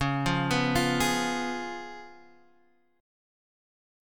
Db7 Chord
Listen to Db7 strummed